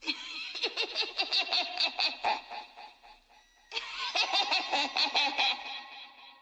FUCK LAUGH.wav